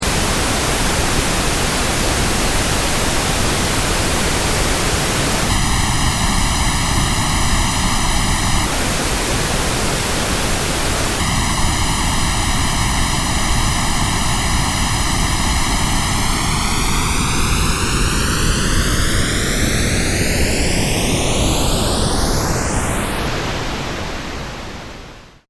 c'est du bruit rose "normale", un mix de deux voix avec le même signal; puis à environ 6 secondes je delay un des deux d'une millseconde (une seule msec !!) puis je revient à la normale puis encore le même delay 1 milliseconde; et puis l'espèce de phasing qu'on entend ensuite resulte simple du fait que je descend le delay continuellement de 1msec à 0,1msec